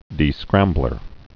(dē-skrămblər)